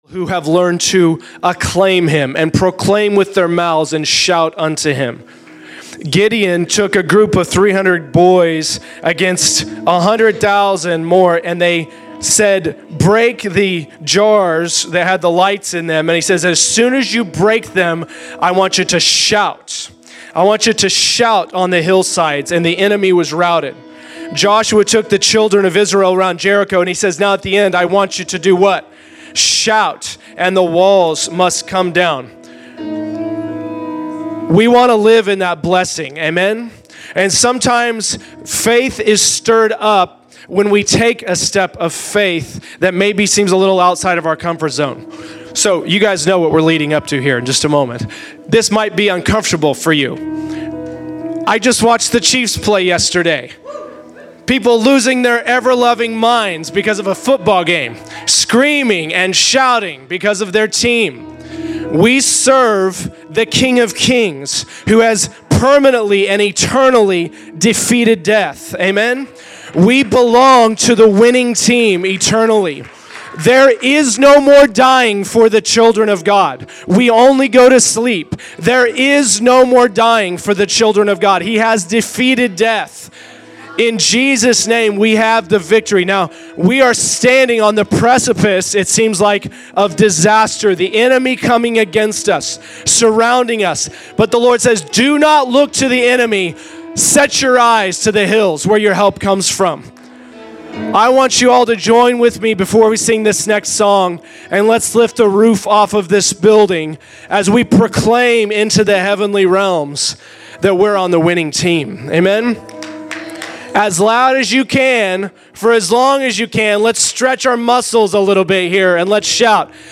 Shout - A Word During Worship
Exhortation